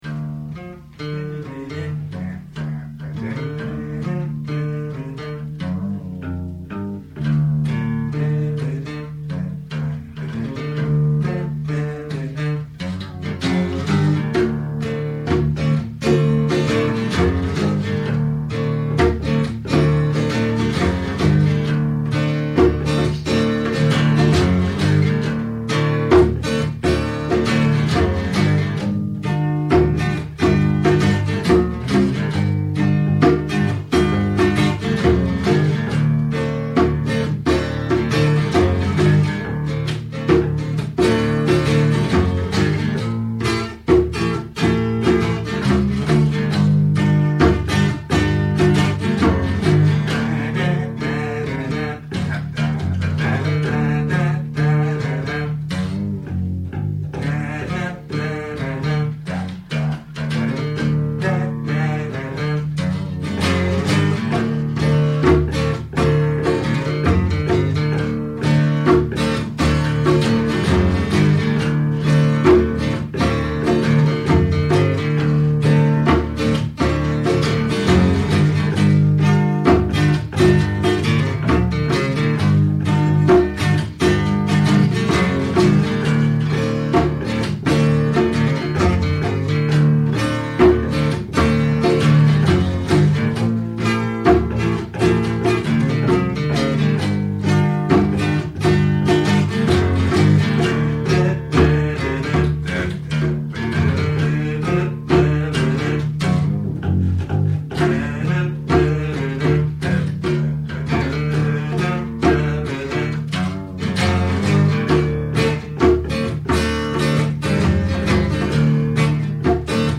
Bass